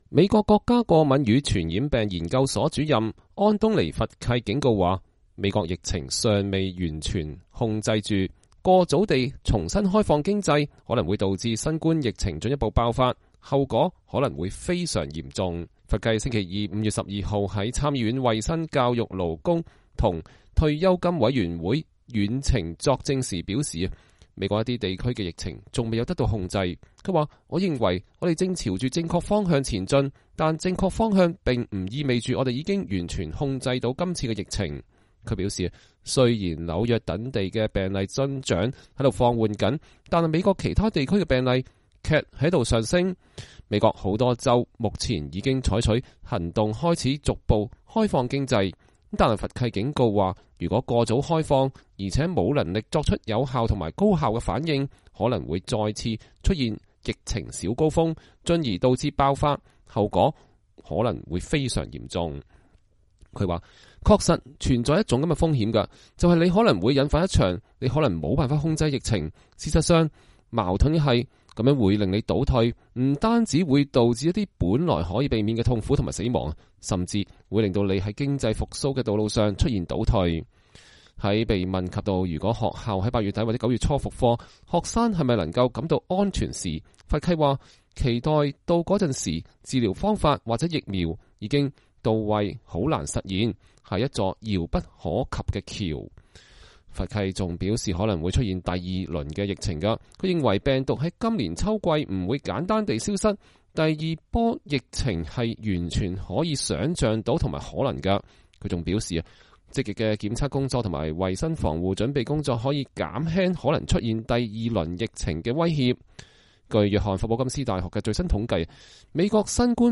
弗契在參議院衛生、教育、勞工和退休金委員會遠程作證。（2020年5月12日）